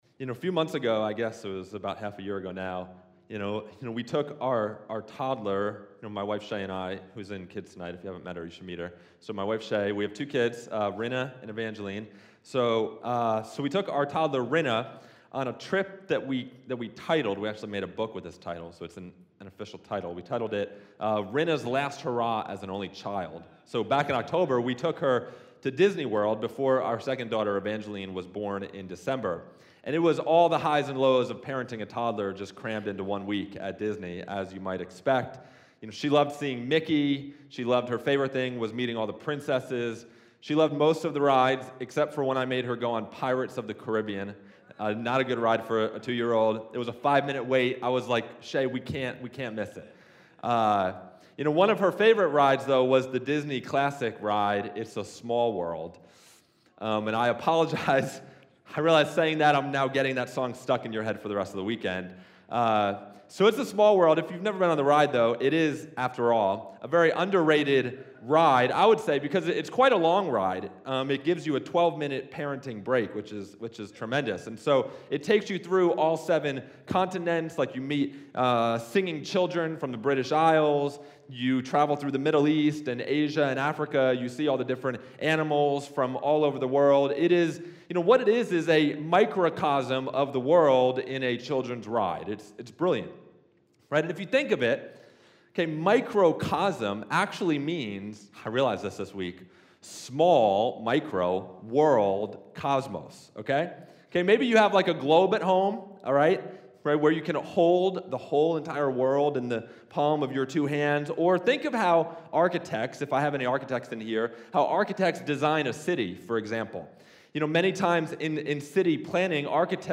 Good-Friday-2026-Sermon-A-Microcosm-of-the-Gospel-on-the-Way-to-the-Cross.mp3